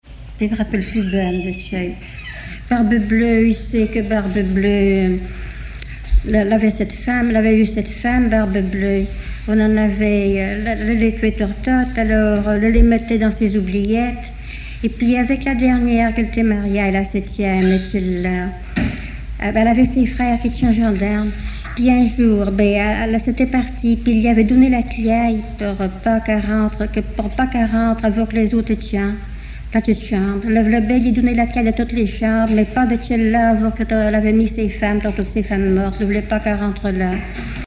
Langue Patois local
Genre conte
Enquête La Soulère, La Roche-sur-Yon